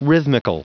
Prononciation du mot rhythmical en anglais (fichier audio)